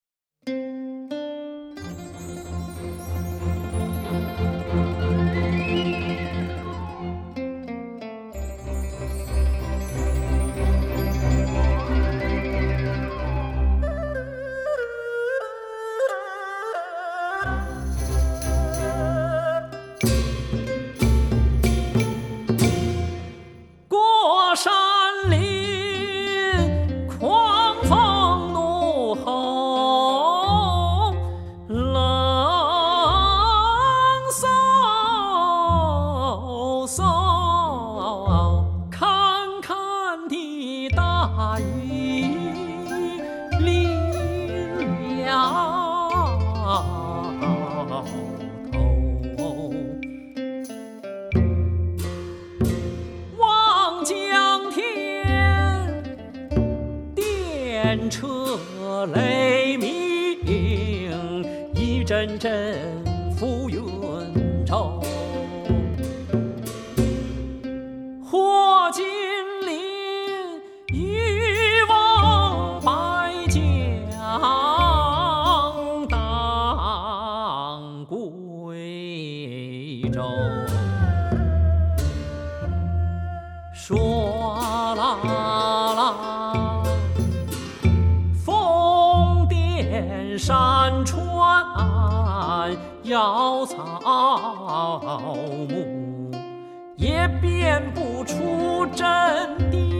在全亞洲最大、設備最先進的中央電視臺 480 平方米錄音棚錄製，音質音色絕佳，音場寬深、定位精準
中阮輕撥短笛急吹，京韻大鼓唱腔剛勁灑脫，怡然自在。